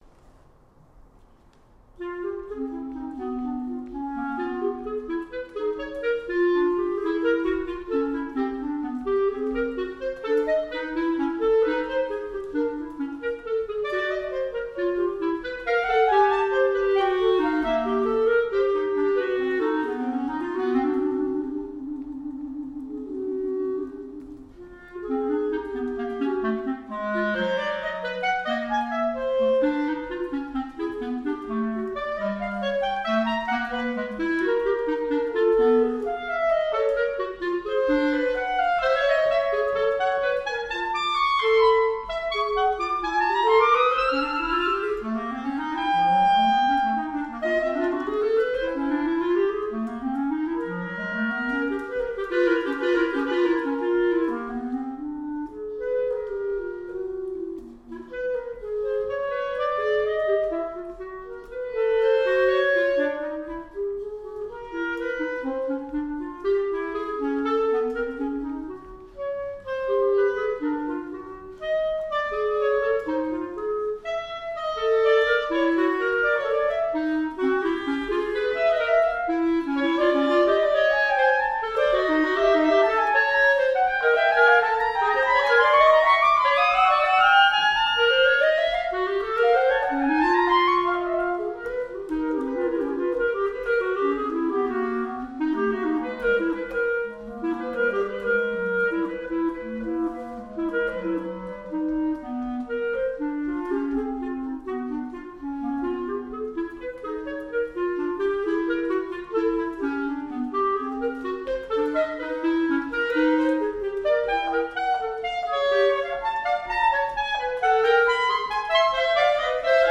2 clarinets in B flat Price
Contemporary Music